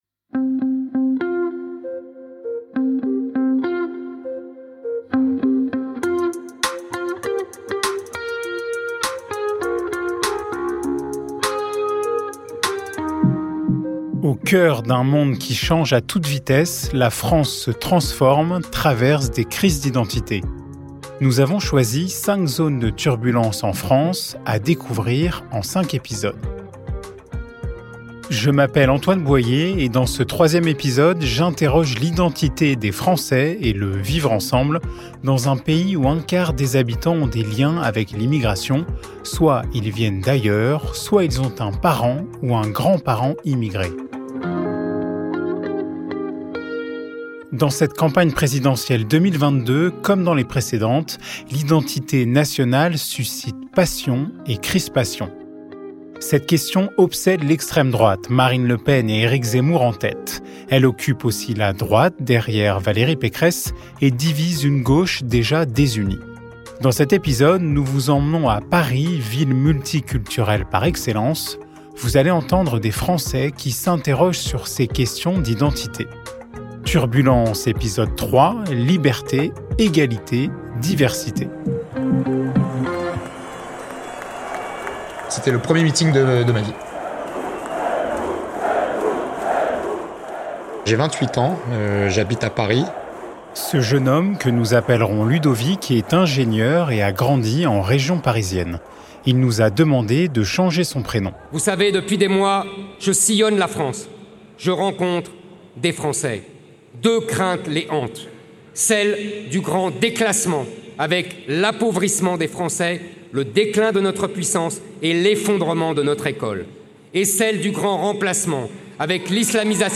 De Villepinte à la Place de la République à Paris, nous partons à la rencontre de Français qui s’interrogent sur leur(s) identités(s), dans un pays de plus en plus multiculturel.